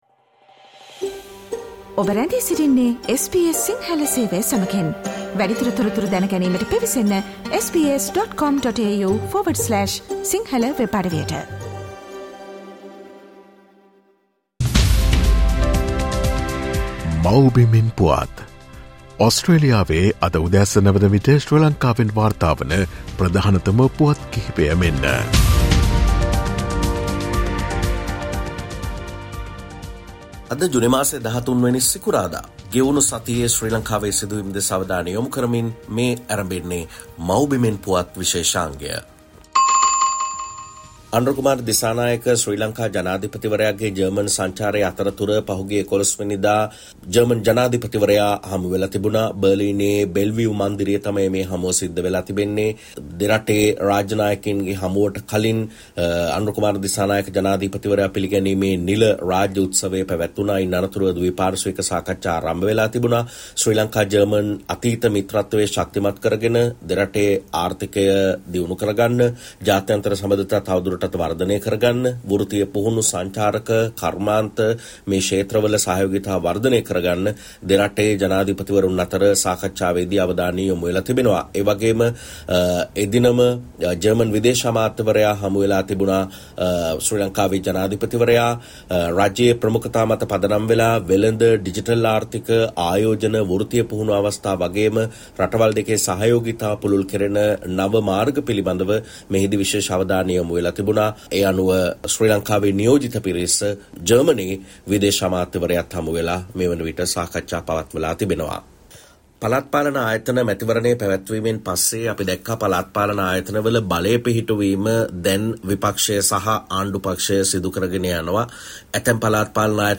ශ්‍රී ලංකාවේ සිට වාර්තා කරයි